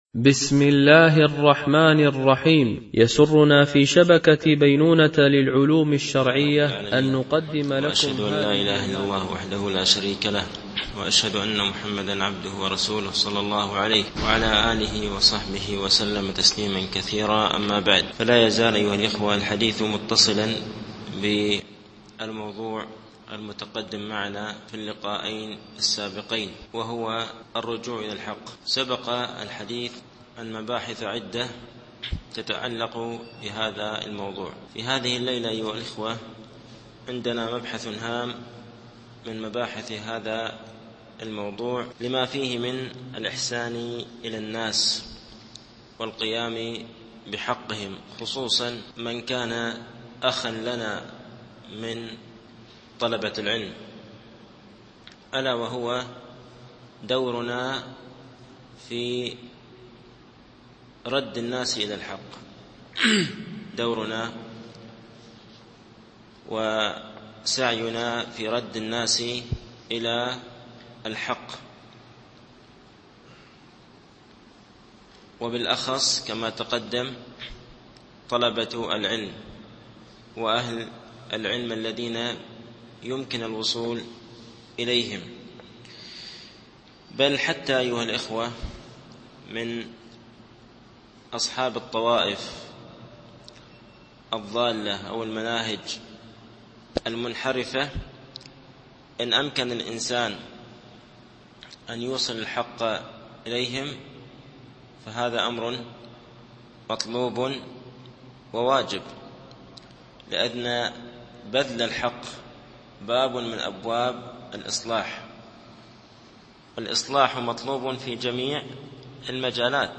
الدرس الرابع و الثلاثون